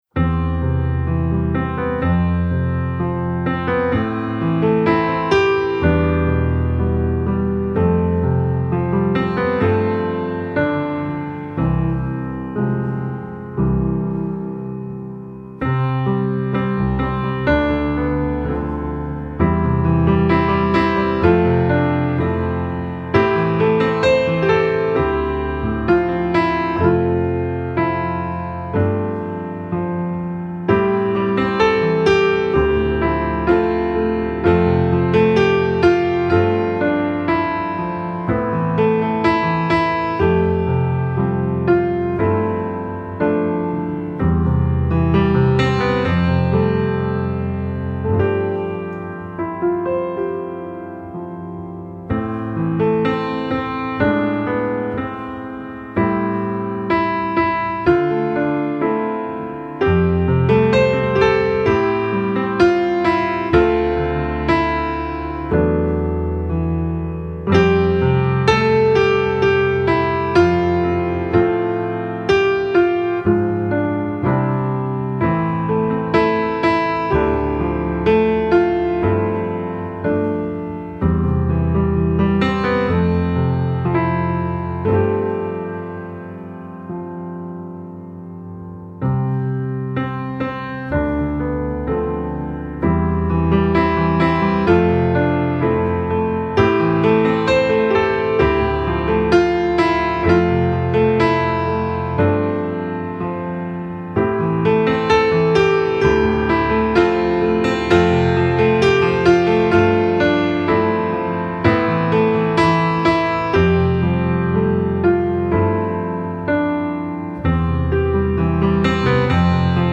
Hymn
dcb7c-beautifulsavior-pianoonly.mp3